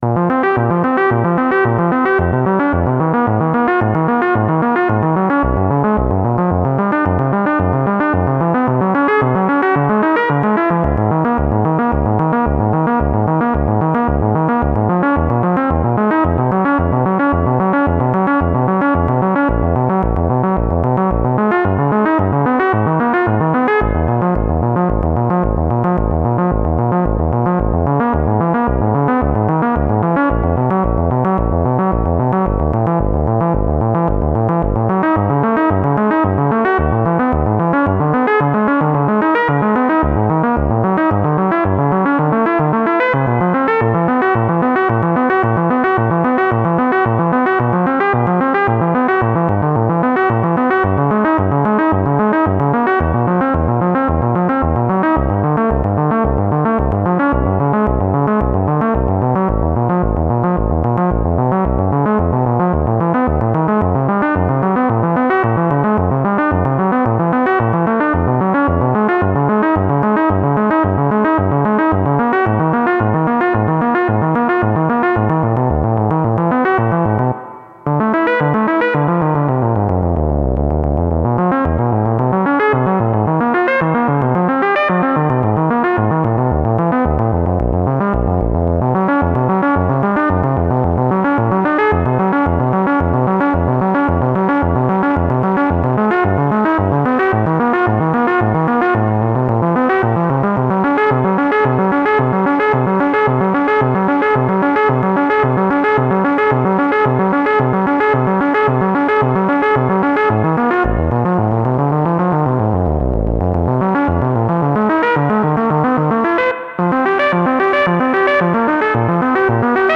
10th May 2012 | Experiment
KORG GR-1 Reverb
Moog Voyager Synthesizer / Voltage-Controlled Oscillator
Roland SPV-355 Synthesizer / Voltage-Controlled Oscillator
Moog CP-251 Option